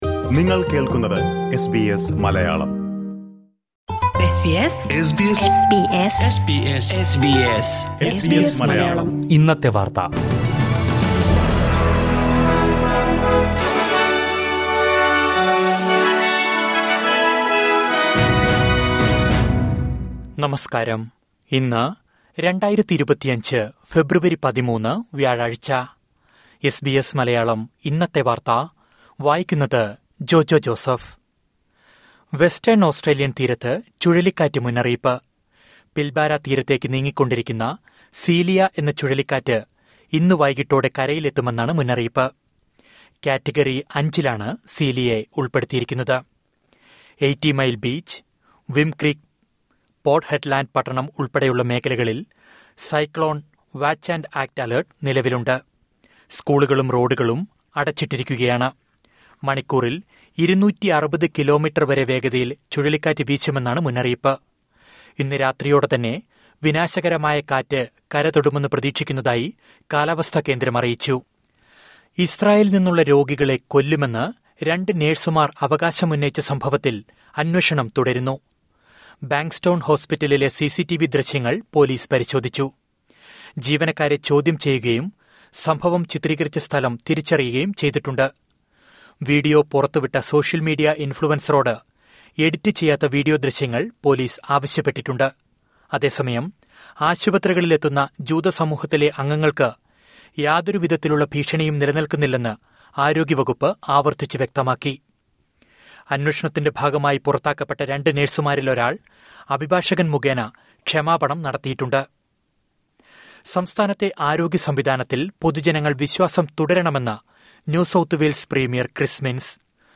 2025 ഫെബ്രുവരി 13ലെ ഓസ്‌ട്രേലിയയിലെ ഏറ്റവും പ്രധാന വാര്‍ത്തകള്‍ കേള്‍ക്കാം...